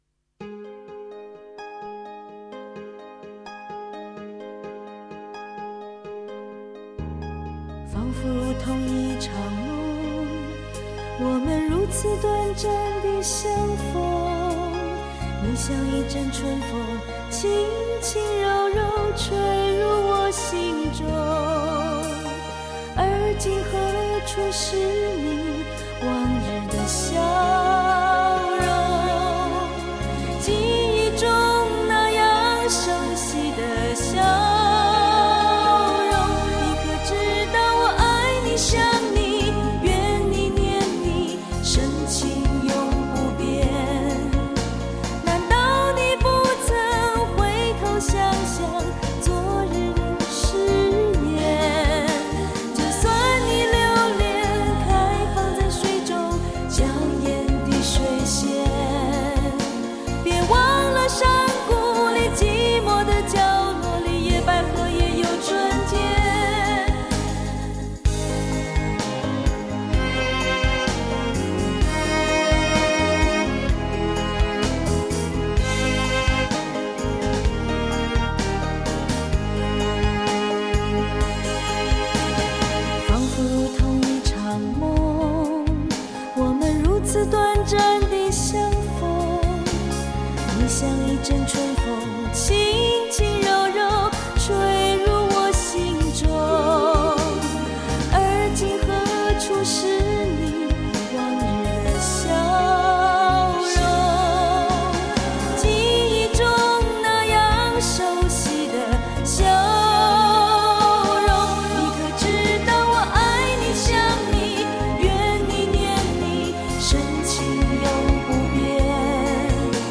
世纪歌谣永恒女唱将